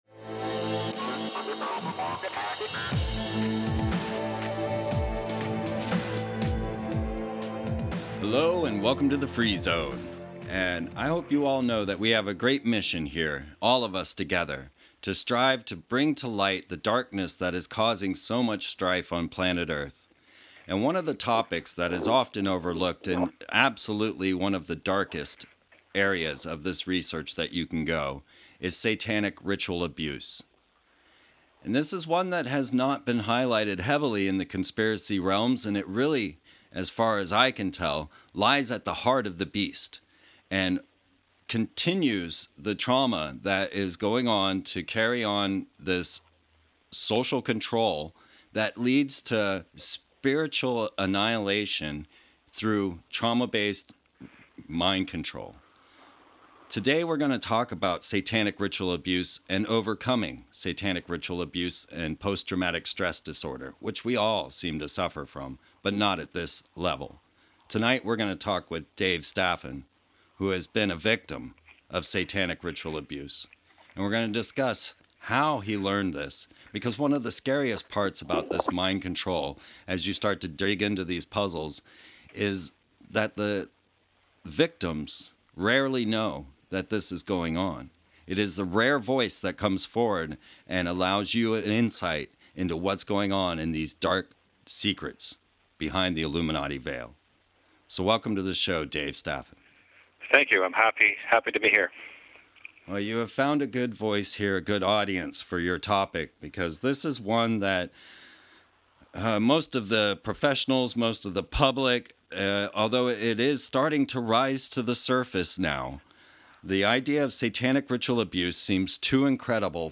Gesprek met een overlevende van satanisch ritueel misbruik